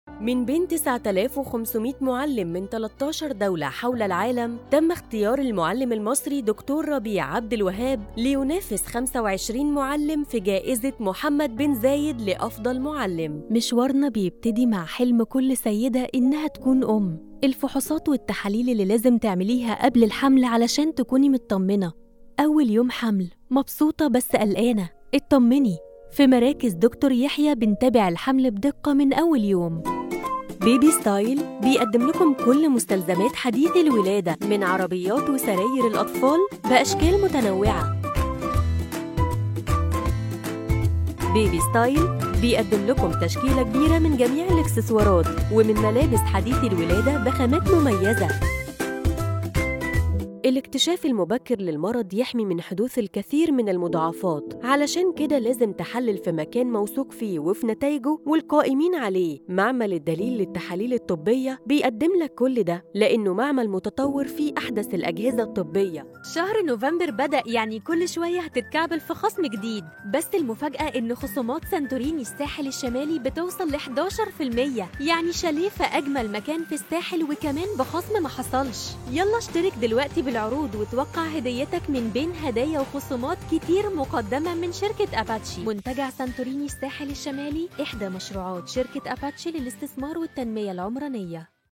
FeMale Voices